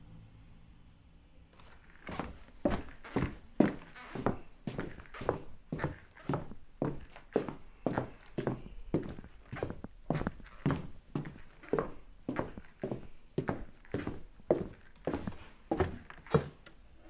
脚步声 " 脚步声靴子(木头)
描述：这个脚步声是用iPhone 5 VoiceMemos应用程序录制的。靴子被用来在室内木地板上创造冲击声。
标签： 脚步 步骤 木材 慢跑 走路
声道立体声